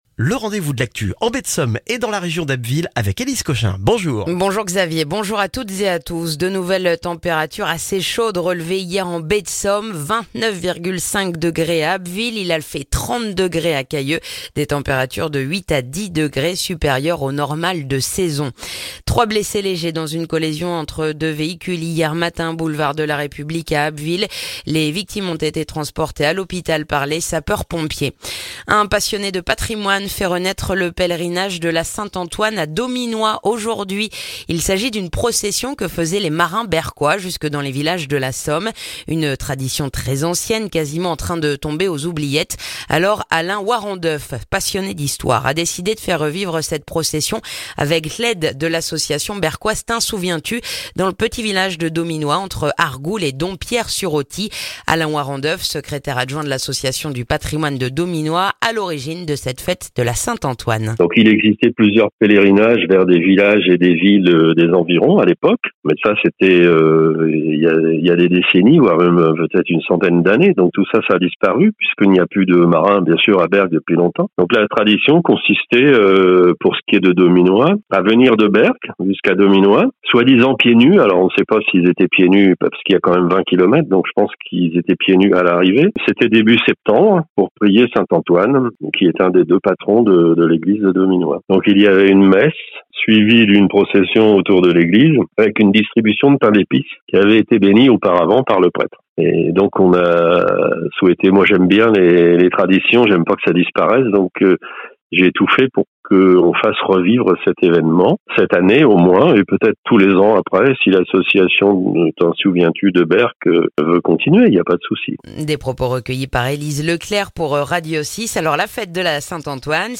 Le journal du vendredi 8 septembre en Baie de Somme et dans la région d'Abbeville